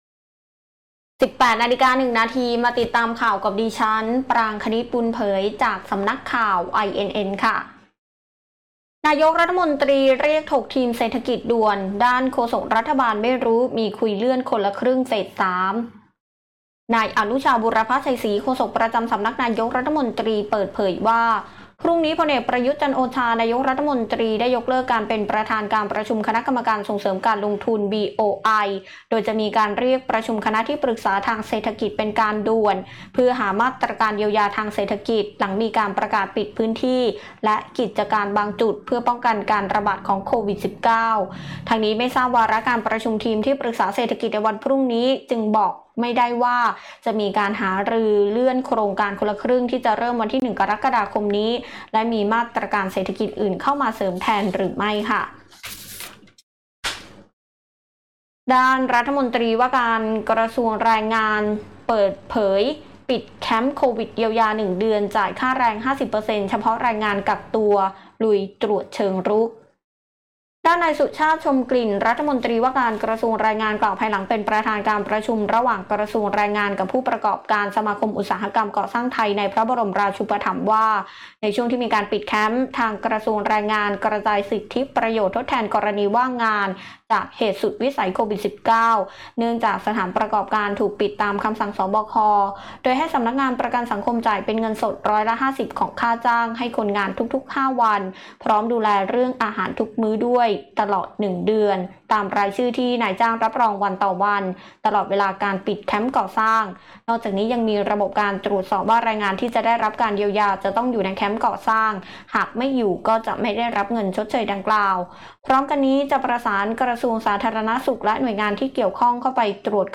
คลิปข่าวต้นชั่วโมง
ข่าวต้นชั่วโมง 18.00 น.